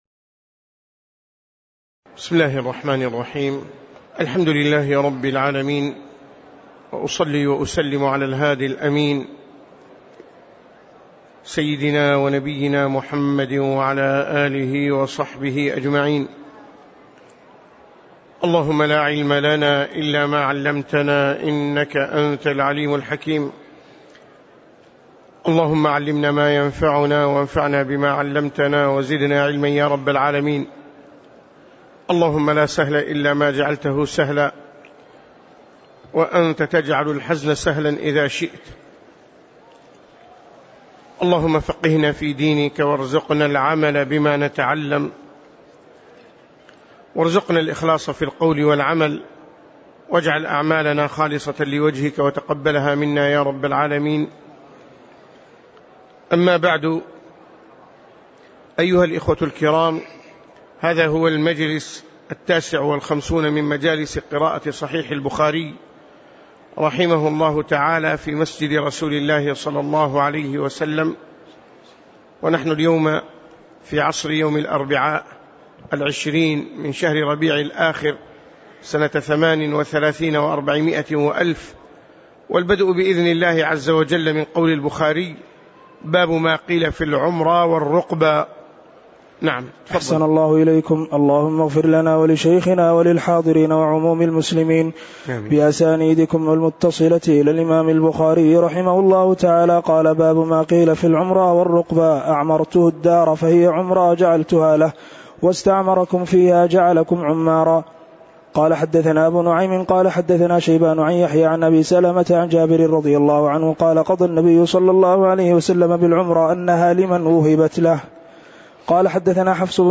تاريخ النشر ٢٠ ربيع الثاني ١٤٣٨ هـ المكان: المسجد النبوي الشيخ